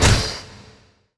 SFX monster_generate_common.wav